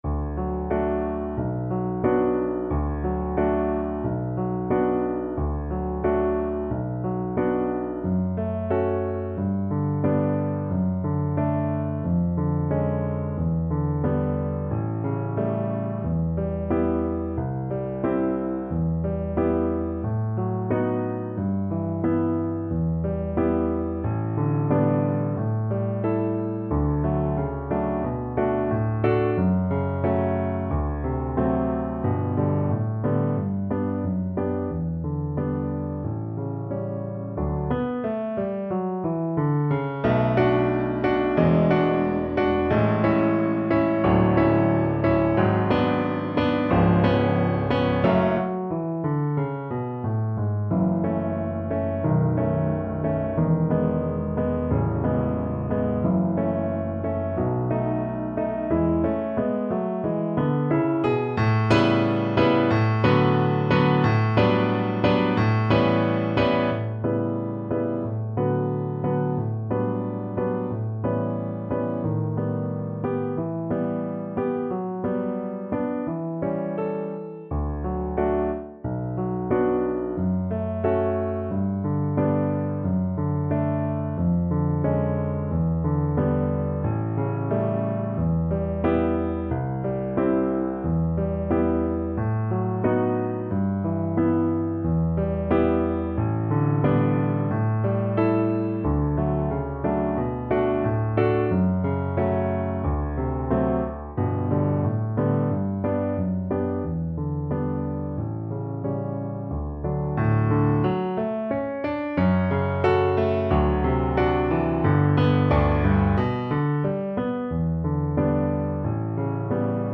2/4 (View more 2/4 Music)
~ = 100 Allegretto con moto =90
Classical (View more Classical Viola Music)